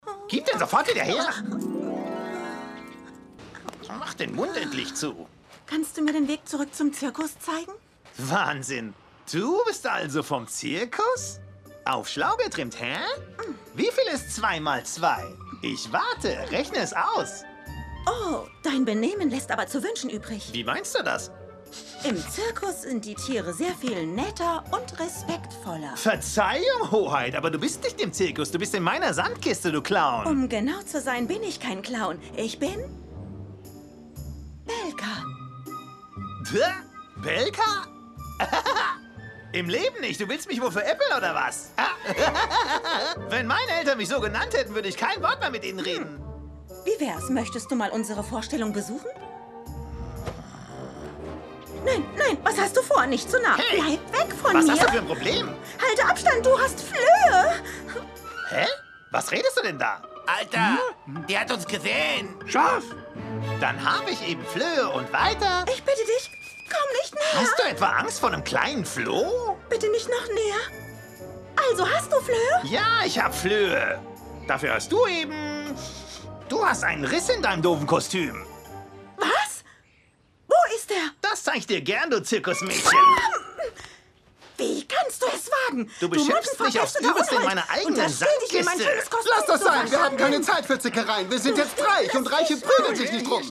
Sonore, warme, sympathische, energetische und glaubwürdige Bass-Bariton-Stimme
Sprechprobe: Sonstiges (Muttersprache):